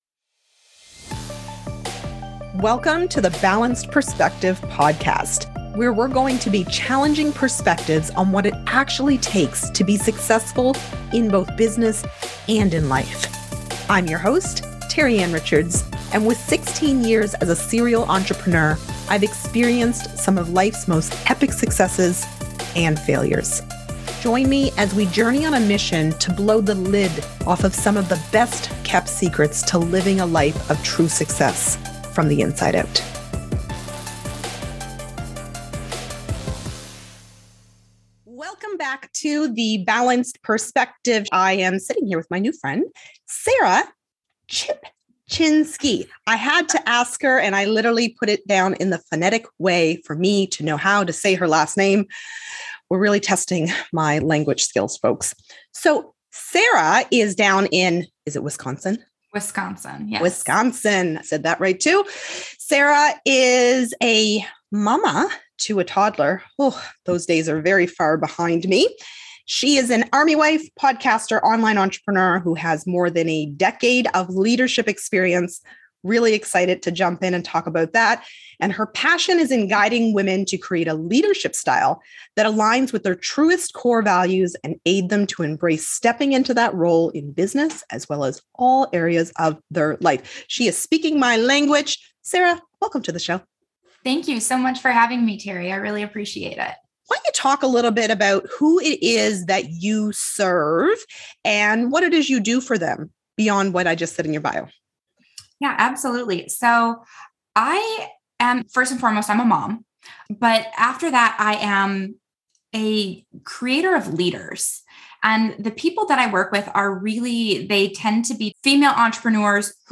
Well, in this episode, I talked to a leadership coach who's molding great leaders and entrepreneurs.